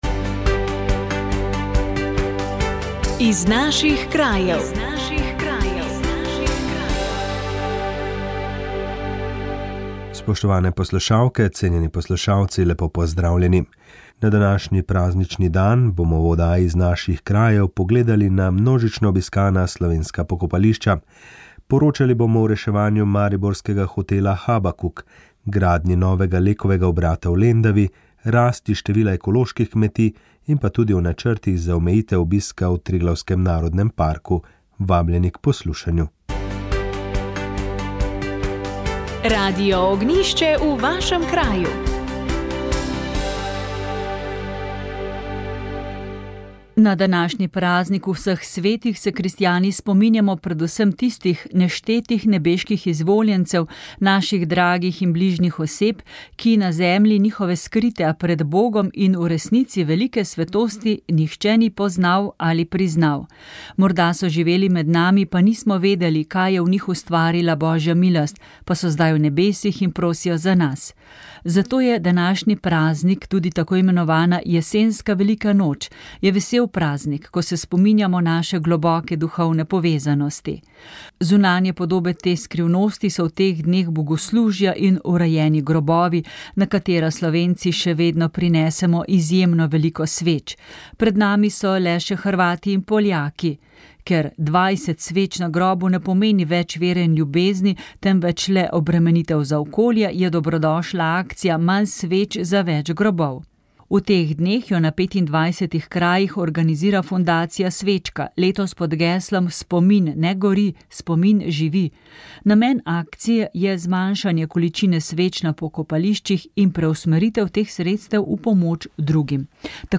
Katere pomembne odločitve bi morali sprejeti, da sploh lahko govorimo prihodnosti? Na to je odgovarjal v tokratni oddaji prof. Petrič. Še prej se je ustavil pri odločitvi za samostojno Slovenijo 23. decembra 1990.